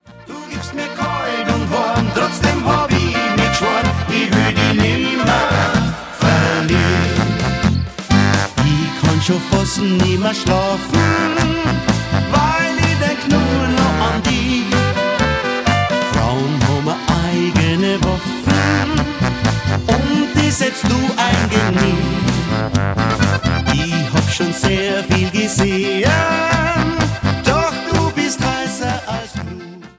Genre: Volkst�mliche Musik Herkunft